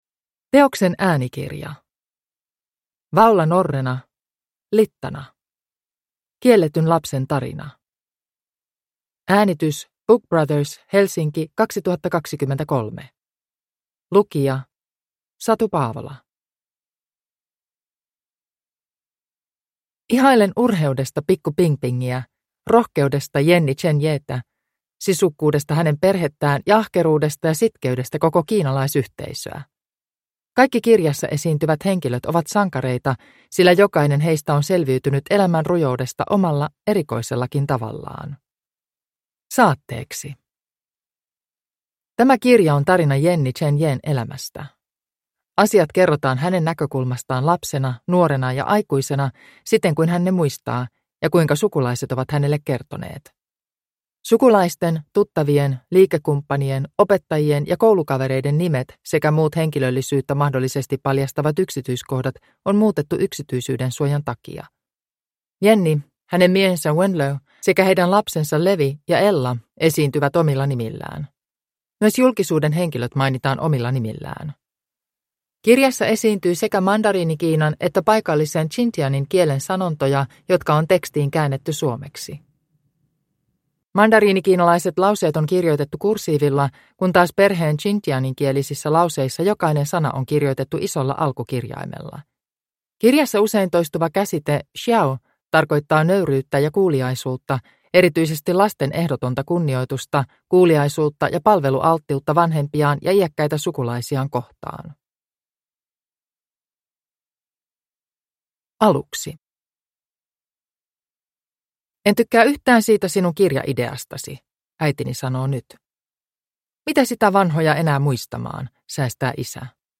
Littana – Ljudbok – Laddas ner